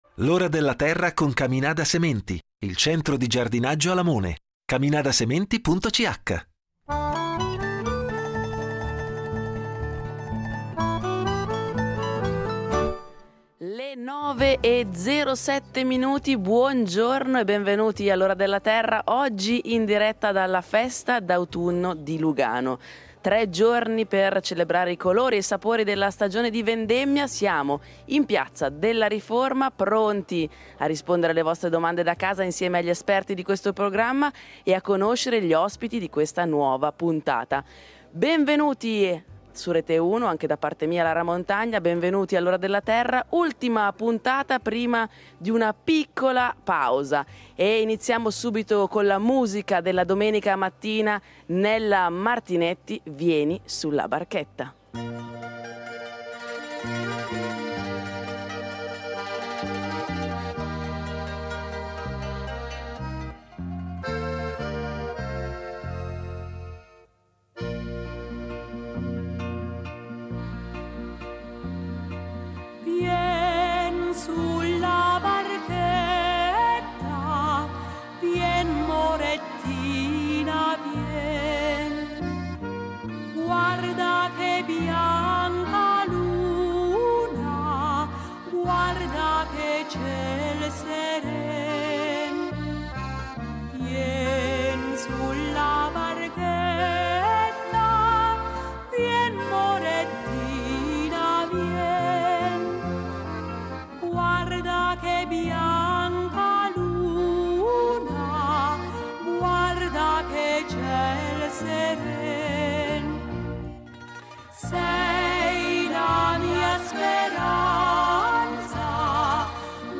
Una manifestazione che da sempre scandisce l’arrivo della stagione delle castagne e del vino. In diretta da Piazza Riforma vi racconteremo delle selve castanili dei patriziati di Lugano e capiremo lo stato attuale dei castagni della Svizzera italiana. Naturalmente non mancheranno i tre esperti del programma, pronti a rispondere alle vostre domande da casa.